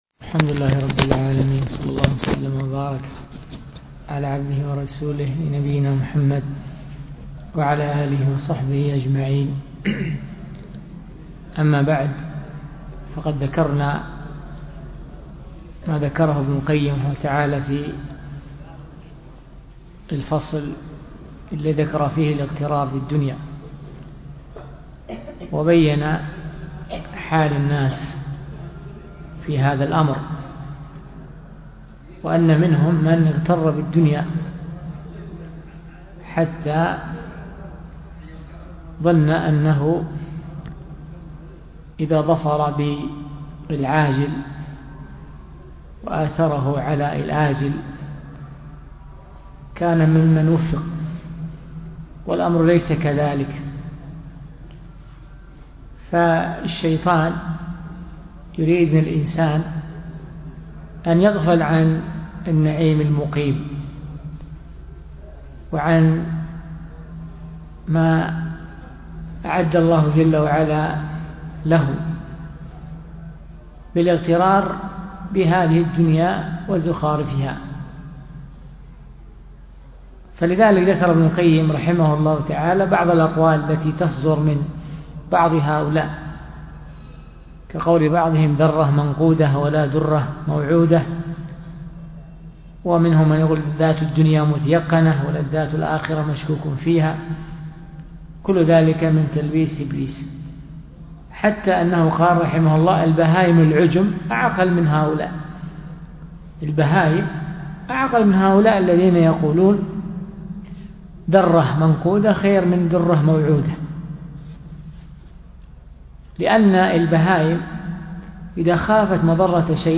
الدرس في الصحيح المسند مما ليس في الصحيحين 503